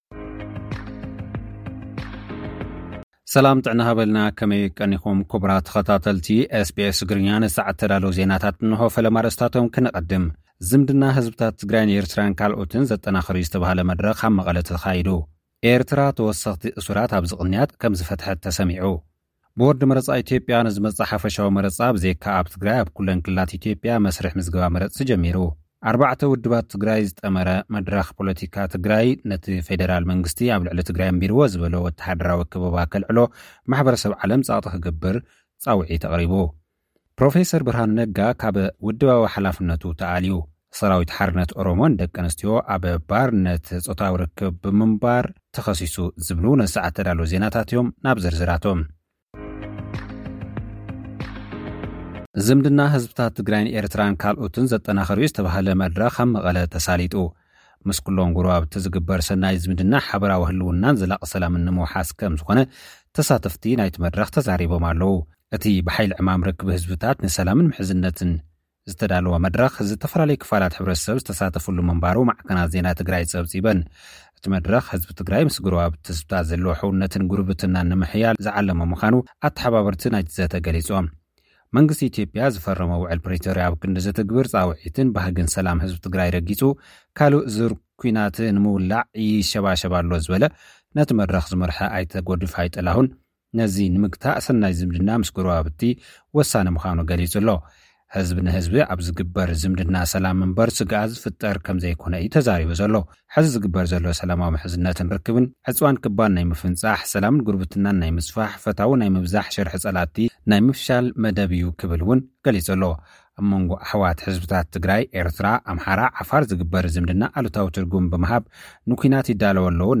ኤምባሲ ኣመሪካ ኣብ ኣዲስ ኣበባ ንኢትዮጵያውያን ናይ ቪዛ መጠንቀቕታ ሂቡ። (ጸብጻብ)